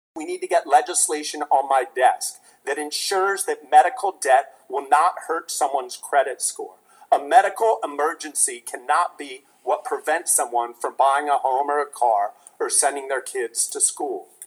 Governor Matt Meyer this afternoon delivered his 2025 State of the State Address before a joint session of the Delaware General Assembly.